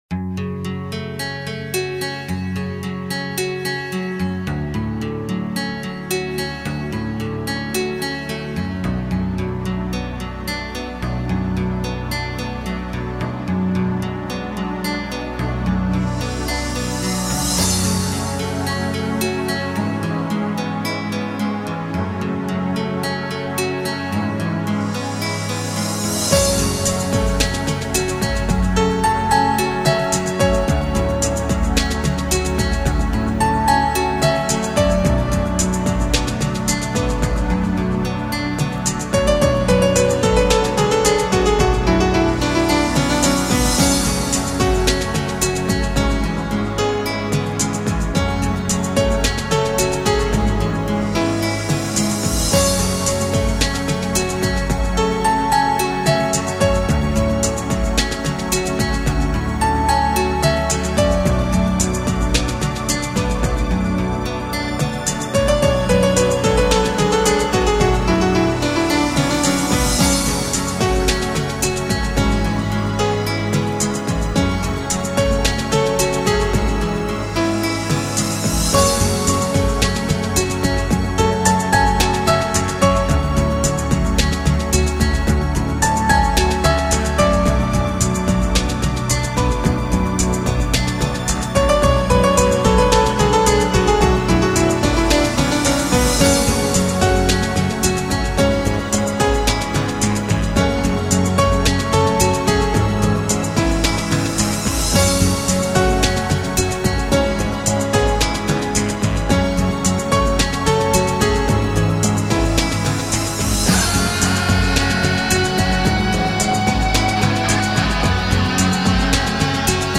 turkmenskaya-muzyka-priyatnaya-muzyka
turkmenskaya_muzyka_priyatnaya_muzyka.mp3